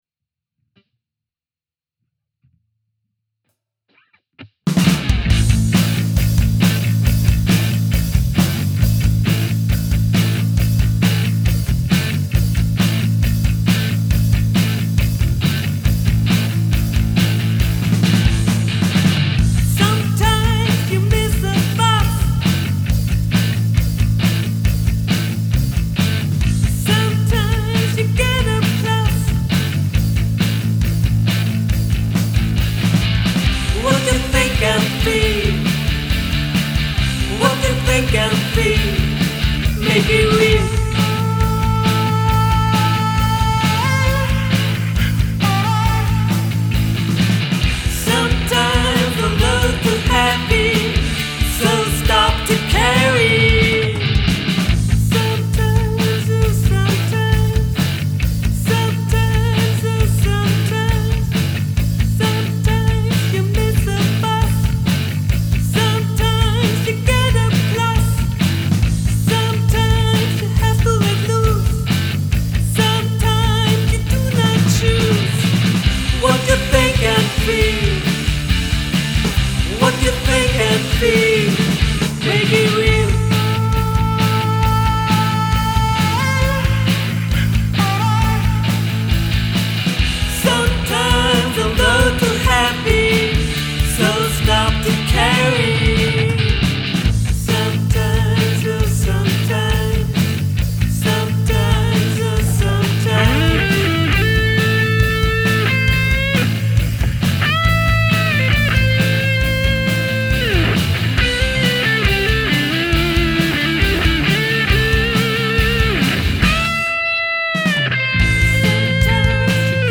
EG+Bs